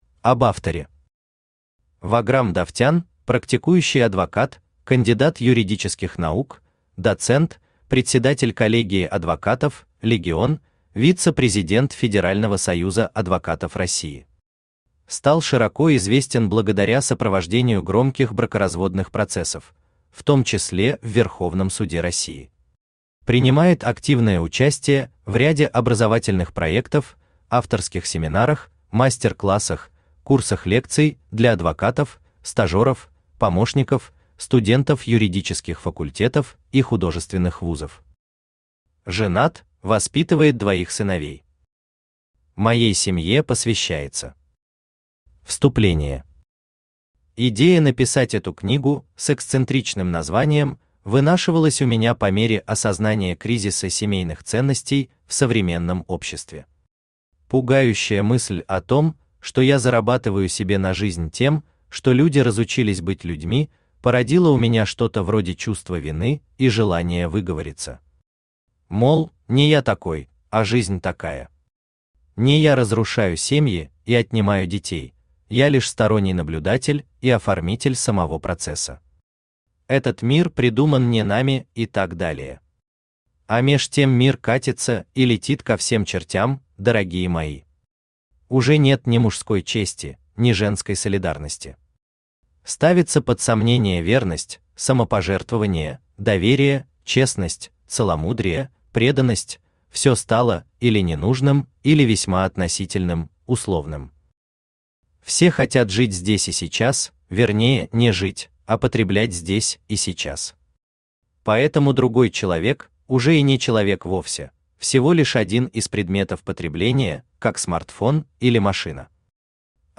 Аудиокнига Пособие «разведёнки», или Лайфхаки от семейного адвоката | Библиотека аудиокниг
Aудиокнига Пособие «разведёнки», или Лайфхаки от семейного адвоката Автор Ваграм Рафикович Давтян Читает аудиокнигу Авточтец ЛитРес.